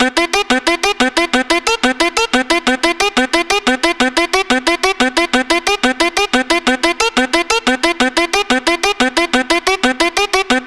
From soulful vocal chops and hypnotic melodies to groovy basslines and punchy drum loops, each sample captures the essence of Ritviz’s unique style.
Gully-Loops-Alag-Hi-Andaj-Drop-Loop-BPM-180-E-Min.wav